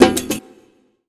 TC2 Perc8.wav